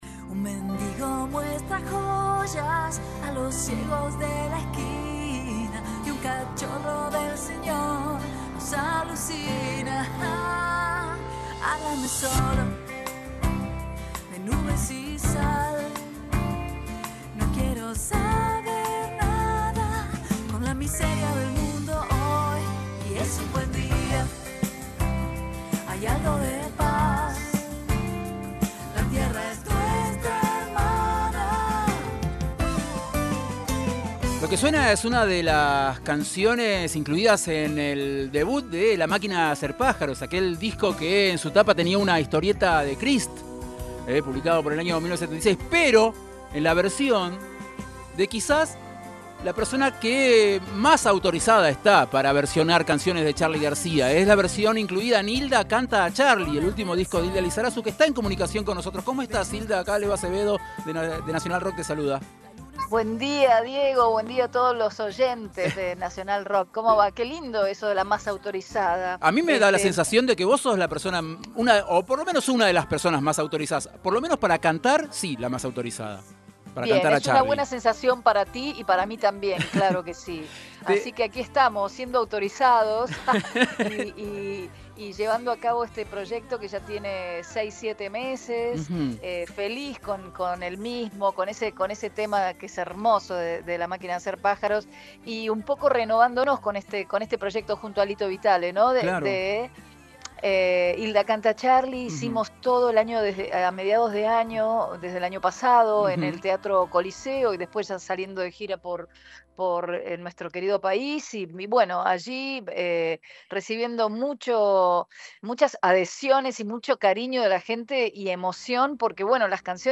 entrevista-hilda-lizarazu.mp3